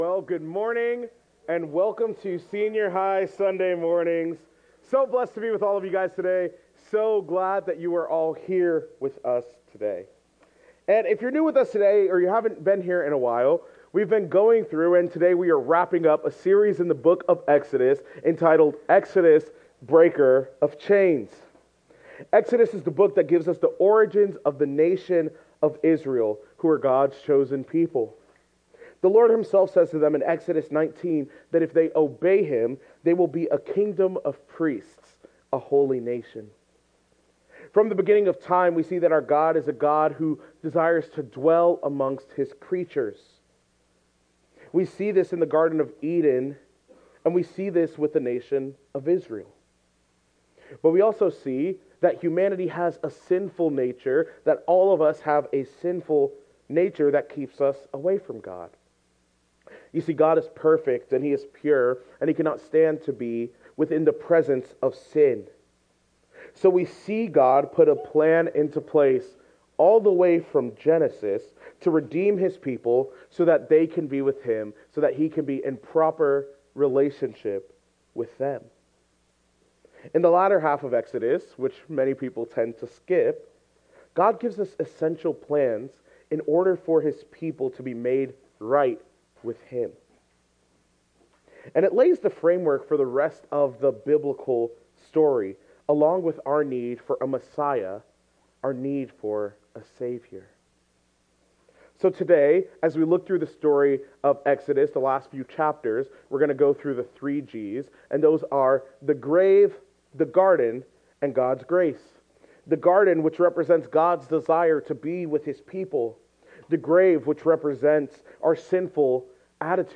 Audio messages from the youth ministry at Reston Bible Church.